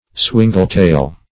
Swingletail \Swin"gle*tail`\, n. (Zool.)